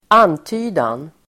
Uttal: [²'an:ty:dan]